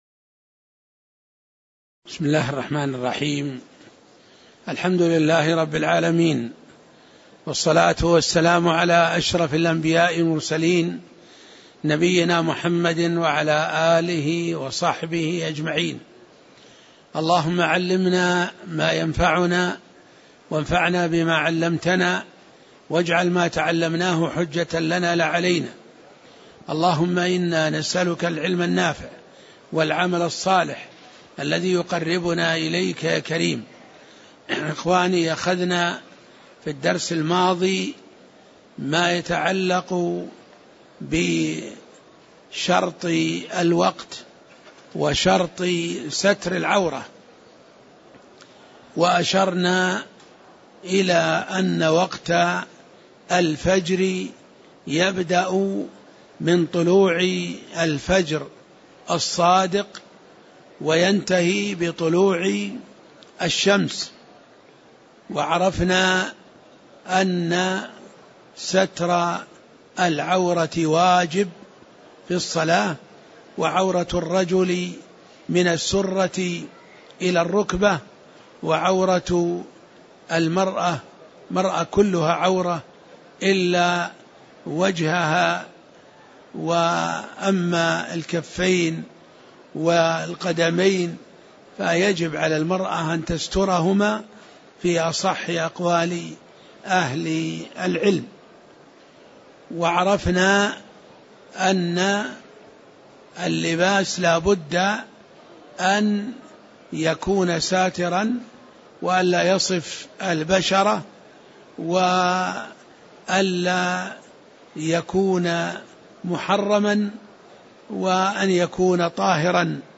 تاريخ النشر ٢ جمادى الأولى ١٤٣٨ هـ المكان: المسجد النبوي الشيخ